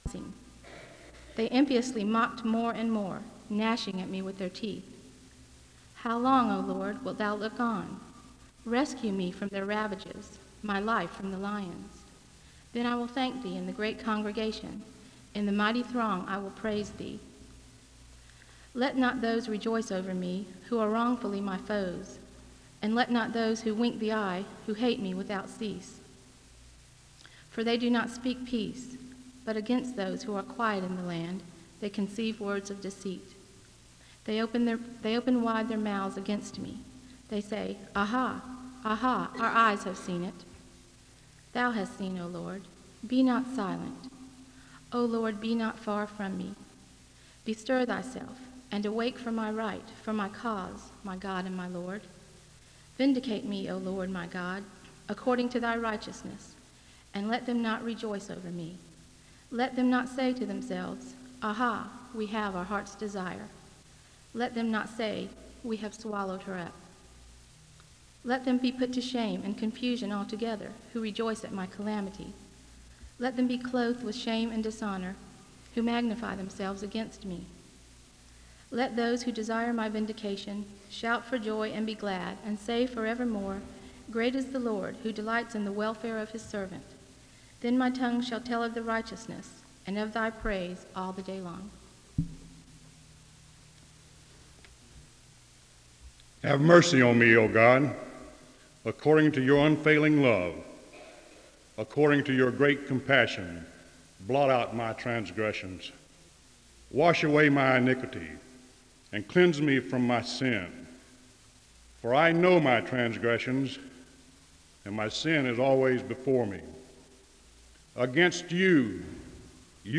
The service begins with Scripture readings (0:00-10:39).
The congregation joins in reading the benediction (19:27-20:08).